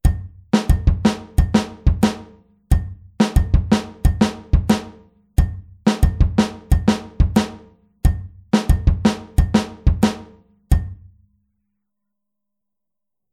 Rechte Hand auf Kopfbecken (bell/head) oder Kuhglocke (cowbell)
Bei der 4tel-Variante spielt die rechte Hand nicht mehr auf dem HiHat sondern wegen des Punches auf dem Kopfbecken (bell/head aber nicht mit der Kuppe) oder der Kuhglocke (cowbell).